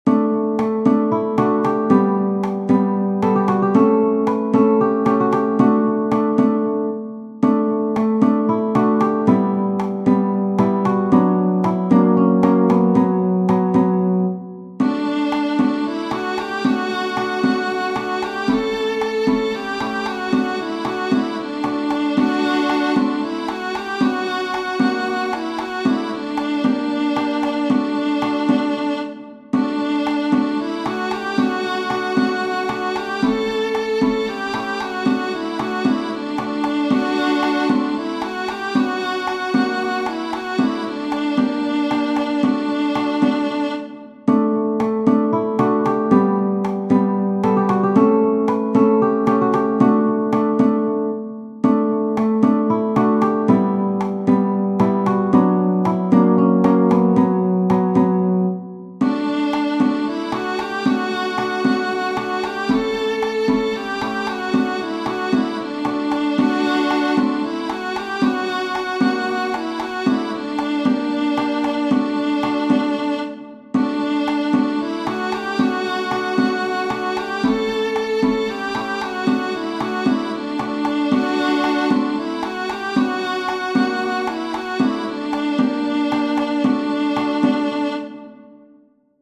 Tradizionale Genere: Folk Testo originale macedone (Traslitterazione) Legnala Dana, zaspala, lele Bože, Vo edna mala gradina.